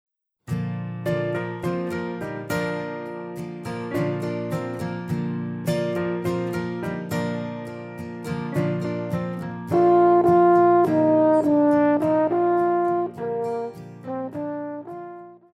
Pop
French Horn
Band
Instrumental
Rock,Country
Only backing